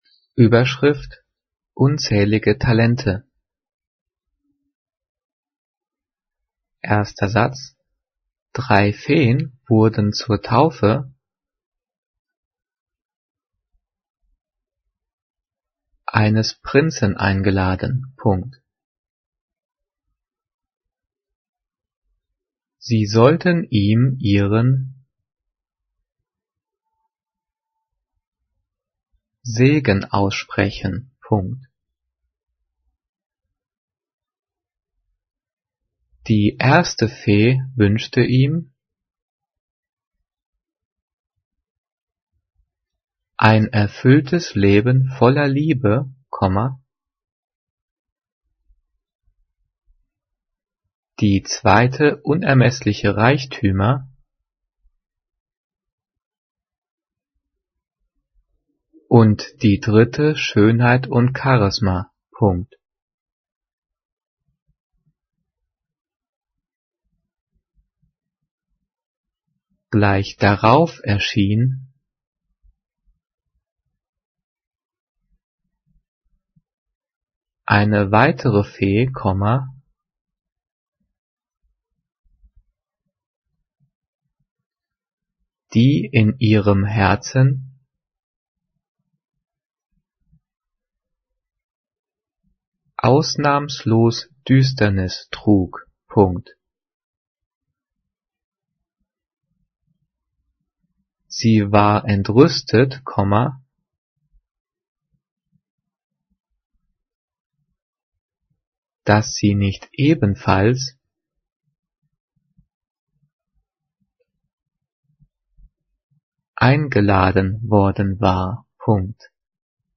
Die vielen Sprechpausen sind dafür da, dass du die Audio-Datei pausierst, um mitzukommen.
Diktiert: